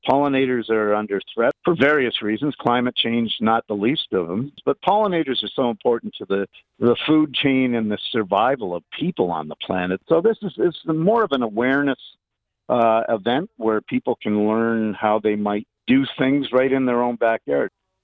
Committee Chair, Councillor Chris Malette says the family-friendly Pollinator Event celebrates Belleville being recognized as a “Bee City” and their efforts to protect pollinators.